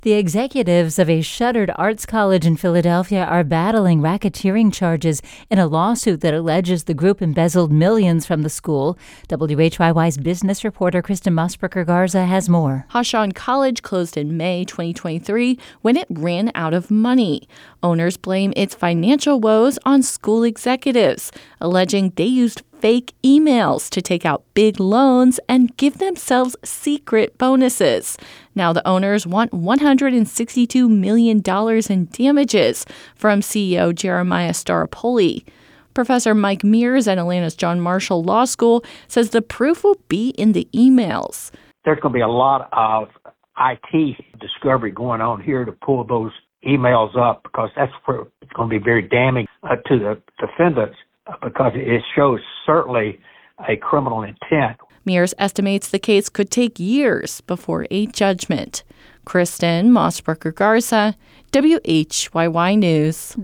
Speaking to a packed hall at the Pennsylvania Convention Center, President Barack Obama sounded a clear call for a top-to-bottom overhaul of the U.S. criminal justice system.